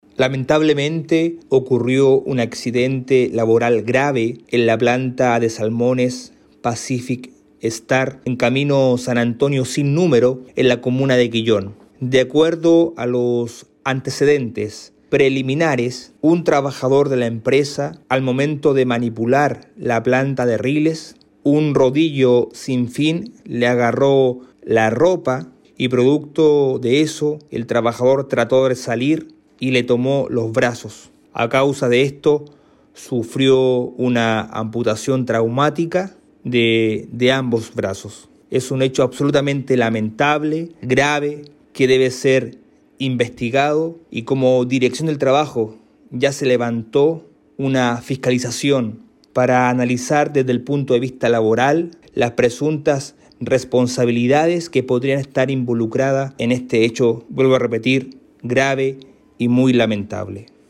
En otro plano, el director regional del Trabajo, Mauro González, calificó como de la máxima gravedad el accidente ocurrido el día lunes por la noche en esta planta salmonera de Quellón y señaló que ya se están recabando los antecedentes para dilucidar si se cumplieron las medidas de seguridad en esa empresa.